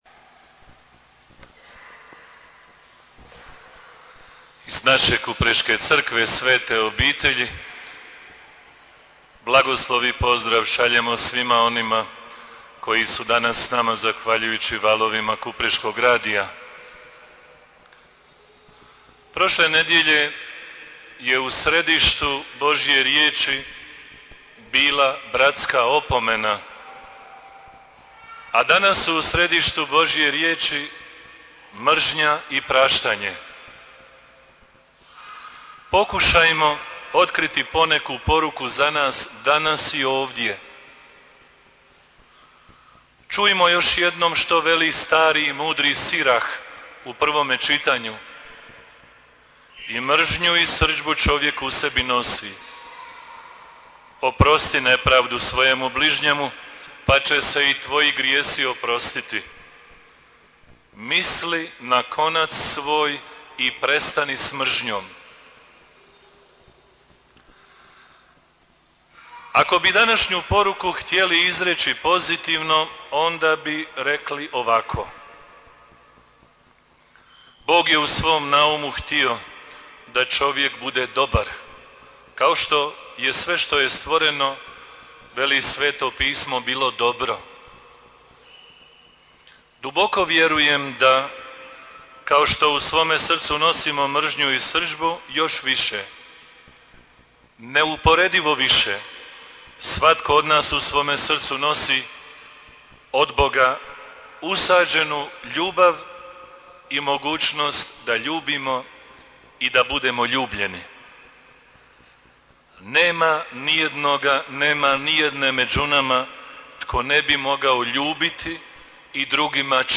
Propovijed
Danas, 17. rujna, 2023. godine u 11:00h slavljena je Sveta misa u Župnoj crkvi Svete Obitelji povodom Dana općine Kupres, koji smo započeli obilježavati u petak, 15. rujna, 2023. a
Sveta_misa_Dan_opcine_Kupres_17_-Copy-2.mp3